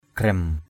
/ɡ͡ɣrɛm/ (đg.) xả miệng rộng = faire des encoches.